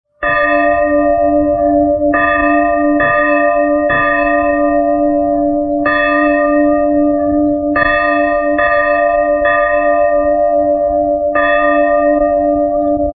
Church bells ringing